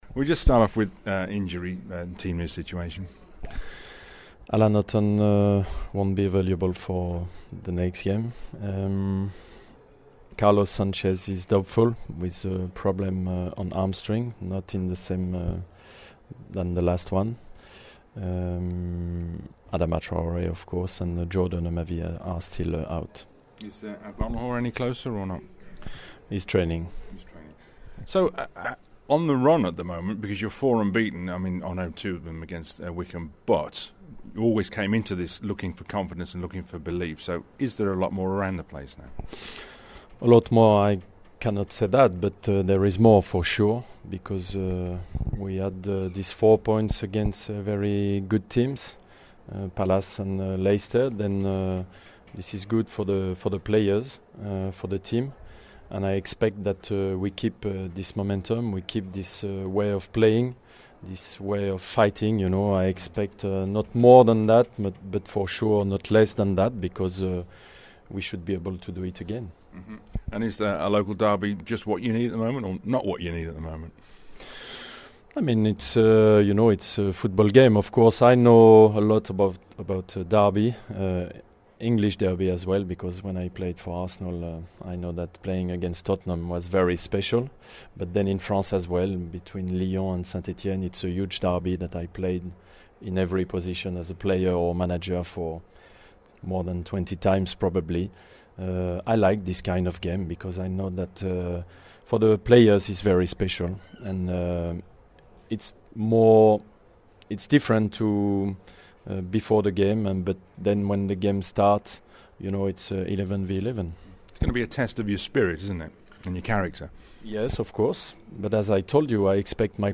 at the Pre West Brom Collective Radio conference at Bodymoor Heath